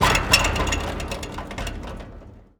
metal_gate_fence_impact_01.wav